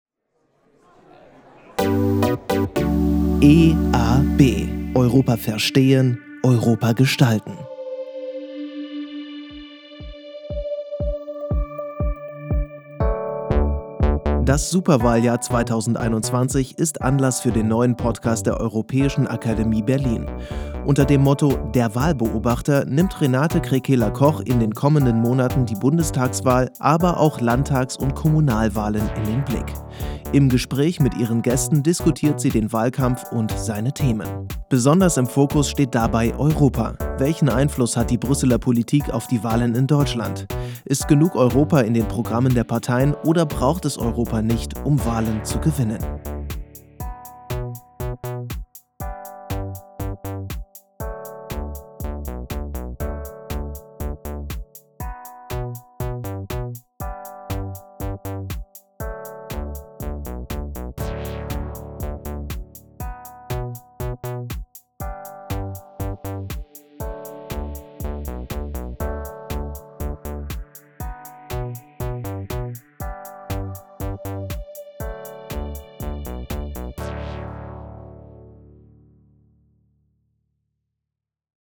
Soundlogo und Intro